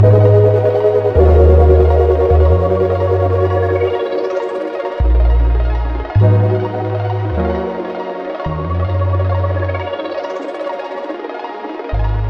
Tag: 78 bpm Hip Hop Loops Synth Loops 2.07 MB wav Key : Unknown Ableton Live